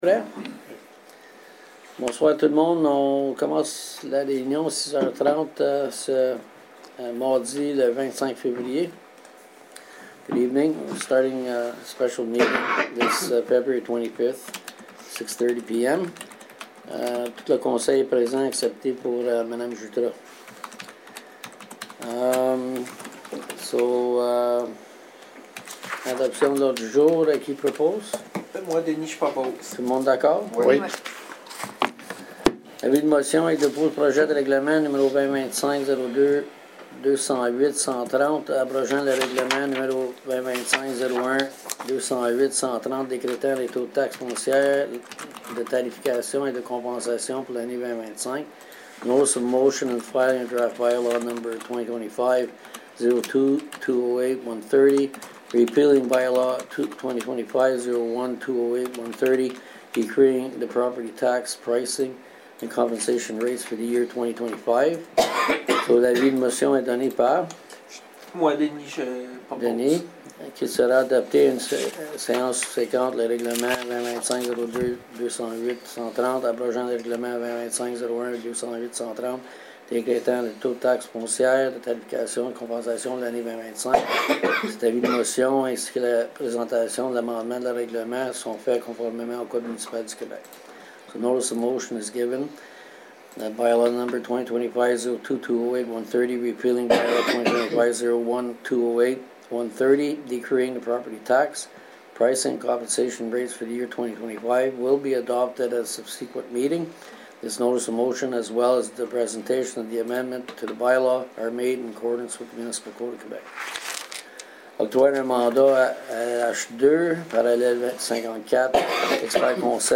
SÉANCE EXTRAORDINAIRE DU 25 FÉVRIER 2025 / SPECIAL MEETING OF FEBRUARY 25, 2025